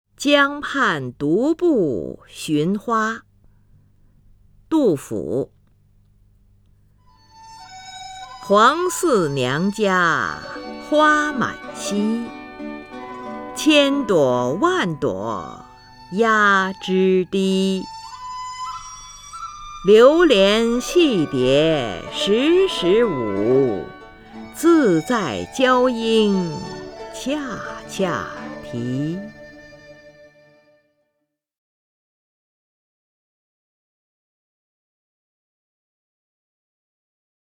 林如朗诵：《江畔独步寻花七绝句·其六》(（唐）杜甫)
名家朗诵欣赏 林如 目录